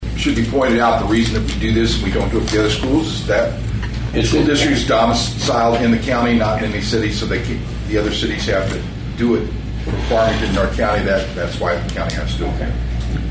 St. Francois County Clerk Kevin Engler explains why the grant has to go through the county, instead of through the West County school district itself.